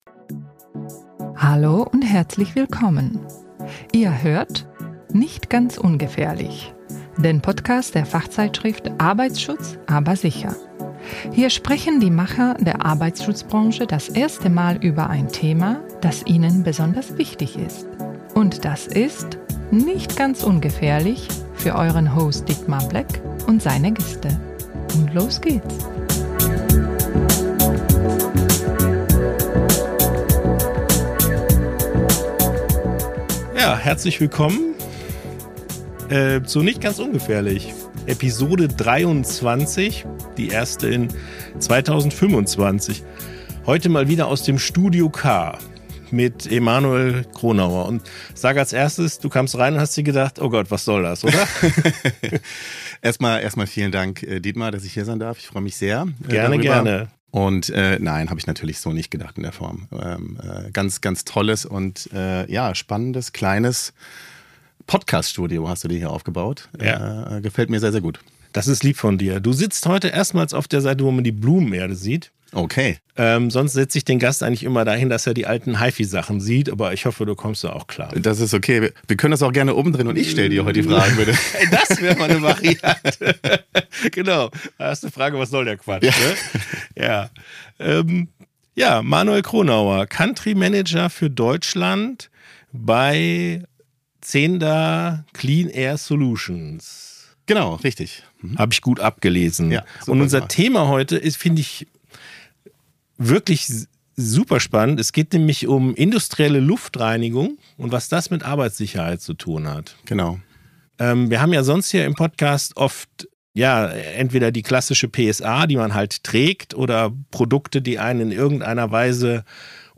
im Studio K in Trier. Im Fokus: der Wert von sauberer Luft für sicheres und produktives Arbeiten in Industrie- und Logistikhallen.
Ein inspirierender Arbeitsschutz-Talk über Arbeitsschutz, Verantwortung und den echten Wert von sauberer Luft am Arbeitsplatz.